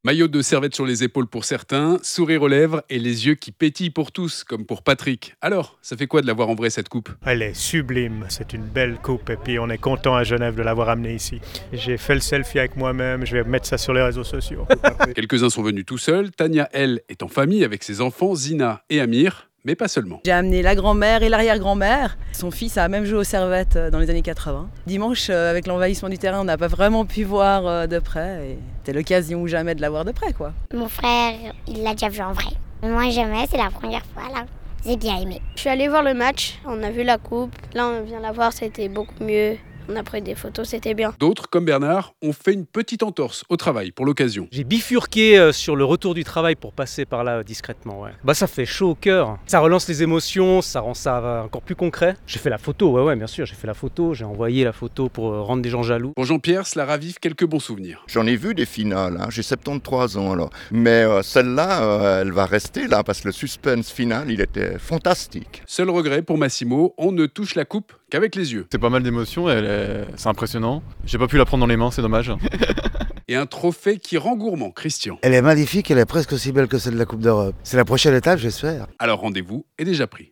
La Coupe de Suisse exposée mercredi après-midi au Palais Eynard pour le plus grand plaisir des supporters du Servette FC